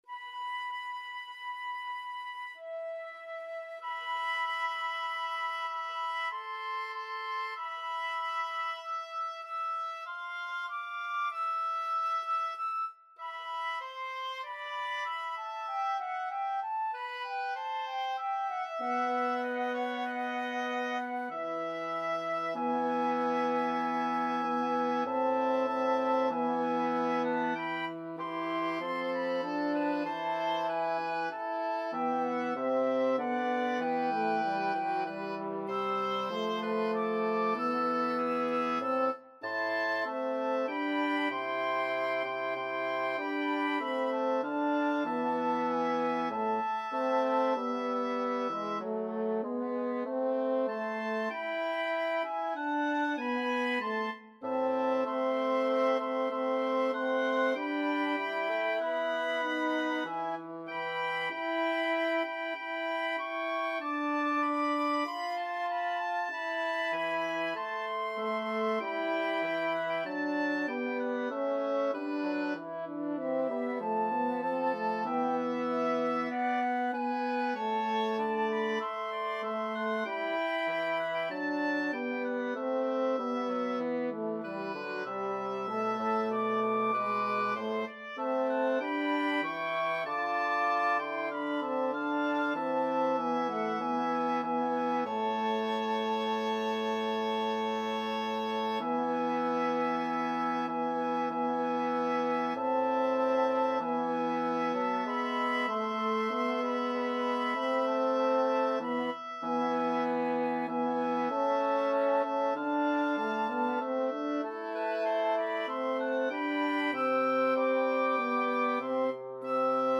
Free Sheet music for Flexible Mixed Ensemble - 4 Players
4/4 (View more 4/4 Music)
A minor (Sounding Pitch) (View more A minor Music for Flexible Mixed Ensemble - 4 Players )
poco rubato tempo = 96-110
Classical (View more Classical Flexible Mixed Ensemble - 4 Players Music)